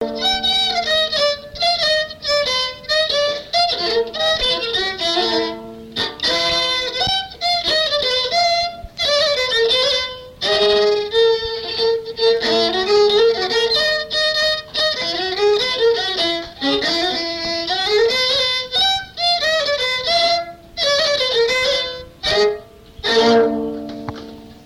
danse : pas de quatre
Pièce musicale inédite